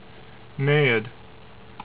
"NAY ed" ) is the innermost of Neptune's known satellites: